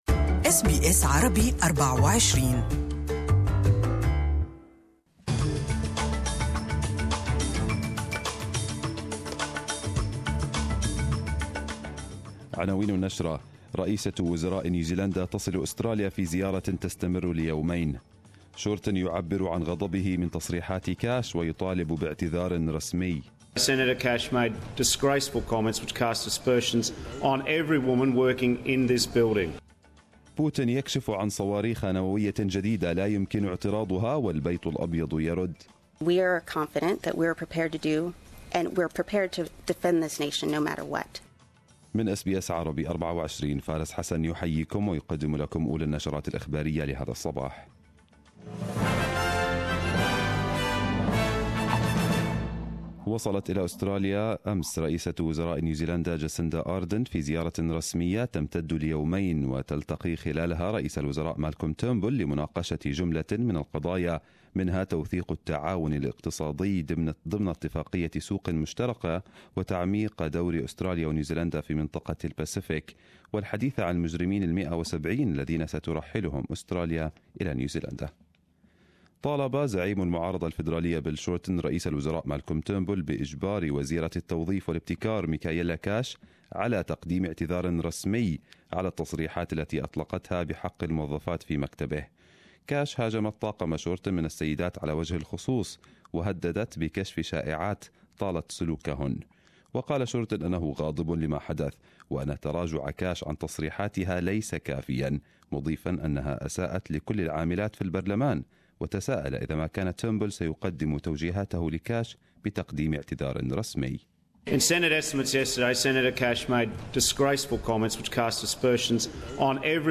Arabic News Bulletin 02/03/2018